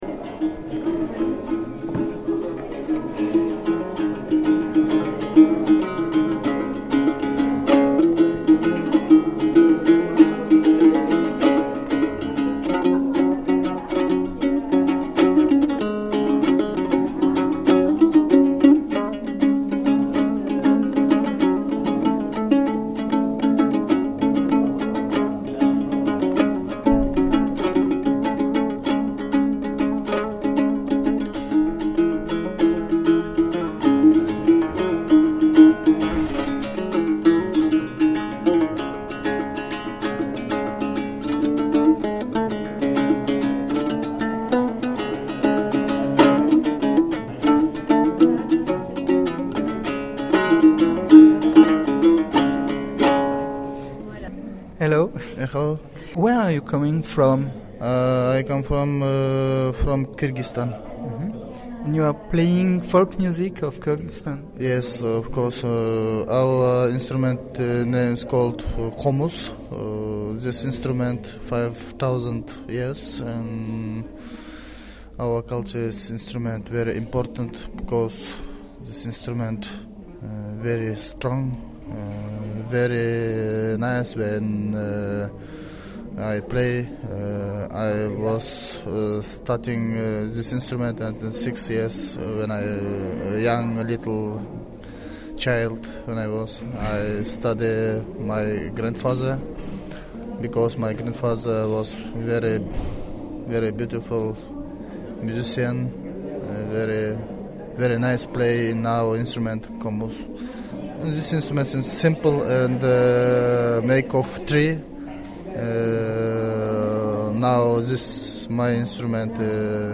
Reportage sur "Résidents du Monde".